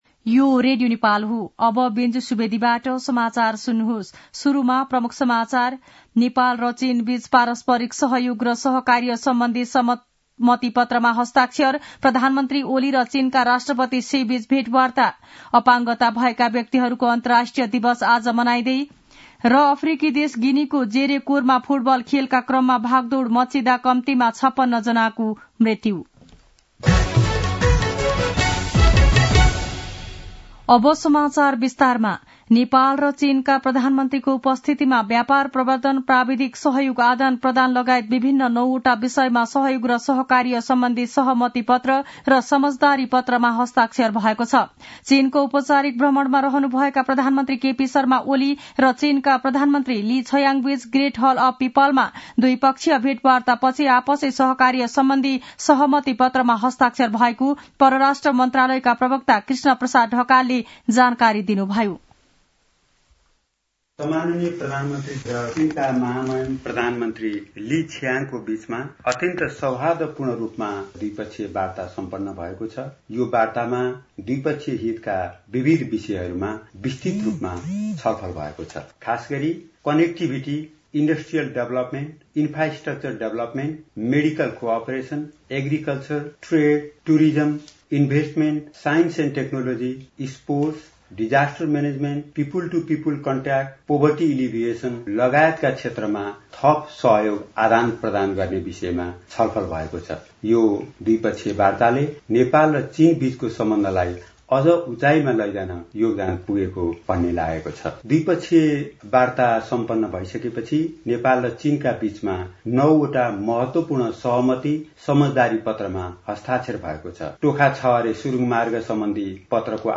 दिउँसो ३ बजेको नेपाली समाचार : १९ मंसिर , २०८१
3-pm-nepali-news-1-2.mp3